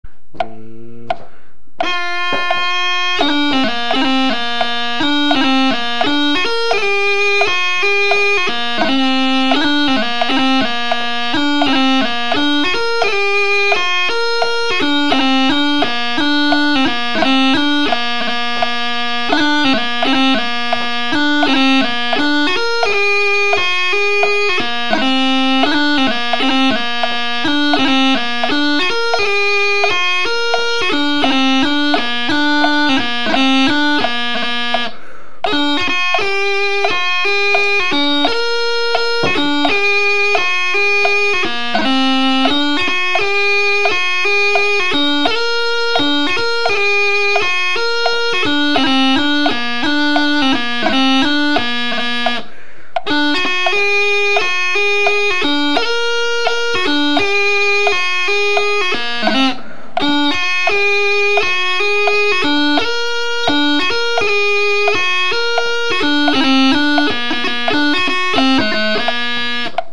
Blustering Home on practice chanter (mp3)